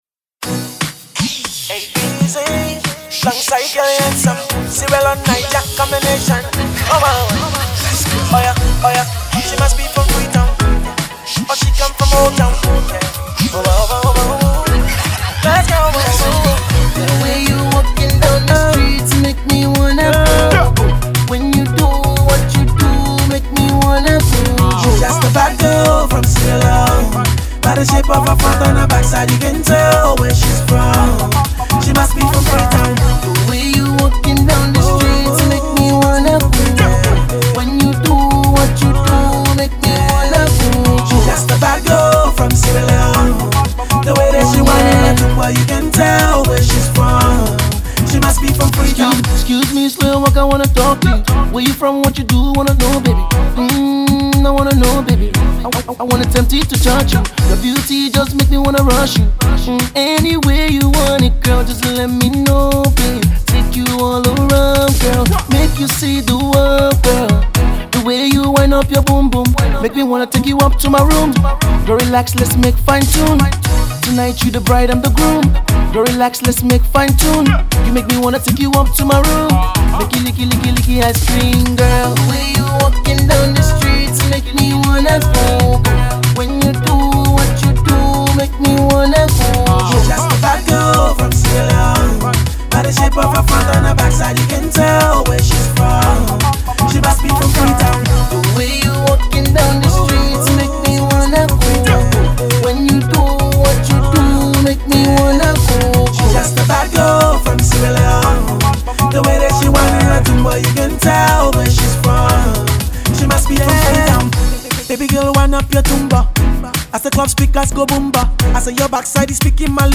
Afro-Pop is slowly becoming more a universal sound.
fun Afro-Pop music